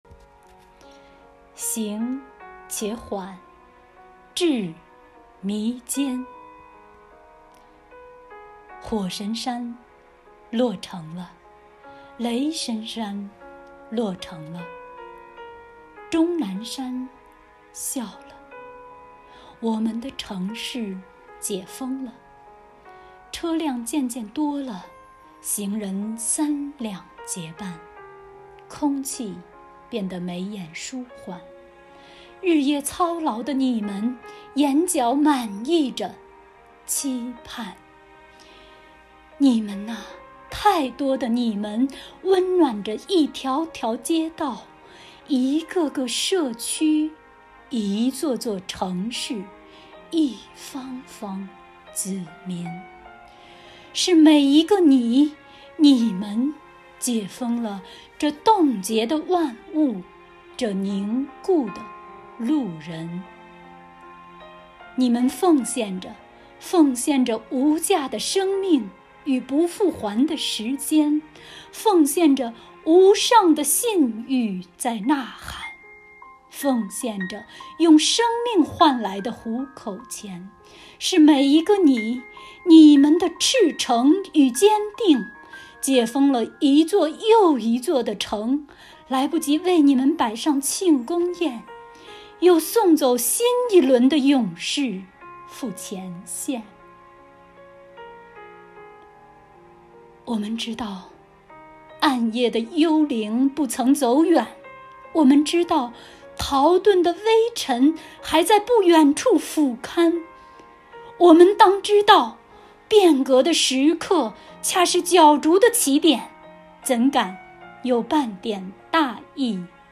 为声援战斗在第一线的工作人员，鼓舞全区人民抗击疫情的信心和决心，本期发布文化工作者创作、录制的系列（Ⅲ）主题歌曲《洁白的蝴蝶》、《为武汉加油》及诵读《行且缓 志弥坚》。